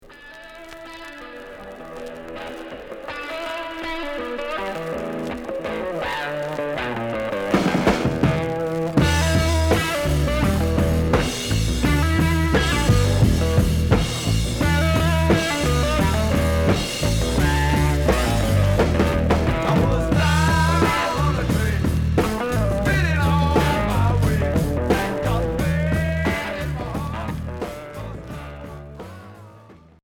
Heavy rock